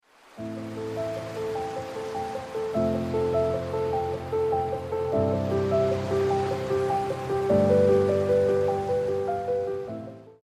Under a galaxy of stars gentle waves caress the quiet shore.